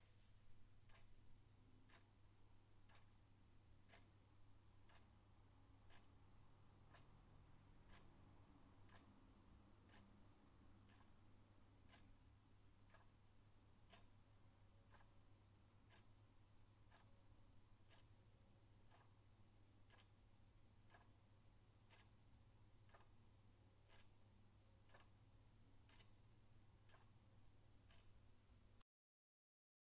描述：这个声音是一个挂钟，在夜间留下来，时钟点击率低。